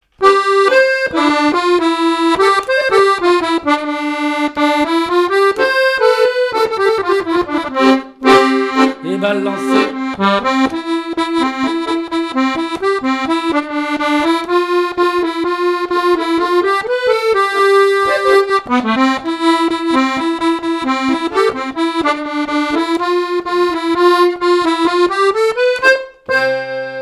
Mémoires et Patrimoines vivants - RaddO est une base de données d'archives iconographiques et sonores.
danse : quadrille
Enquête Compagnons d'EthnoDoc - Arexcpo en Vendée
répertoire de chansons, et d'airs à danser
Pièce musicale inédite